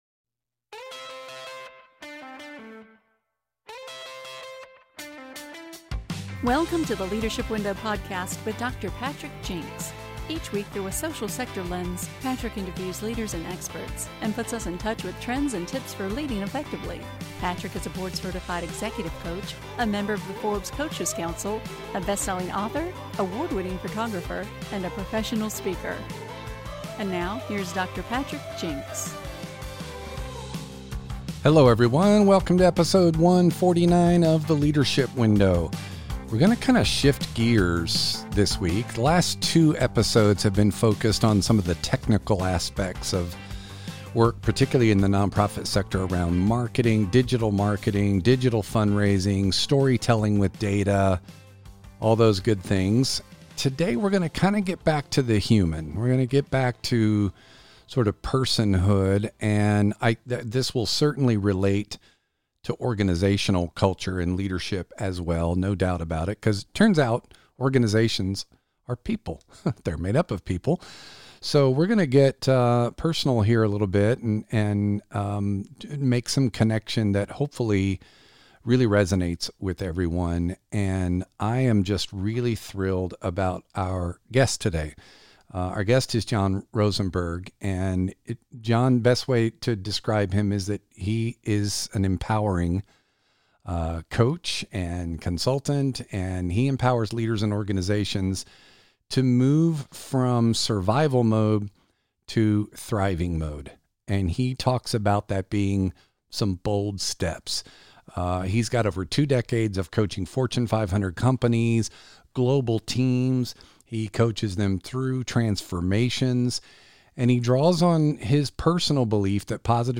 chats